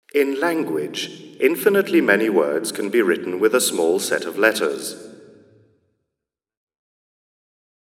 Here is what TP2 sounds like with a medium-format line array (Photo 3). The tight vertical pattern greatly minimizes room excitation – even more so if an audience were present. The C50 is 15 dB A-weighted and the STI is 0.93.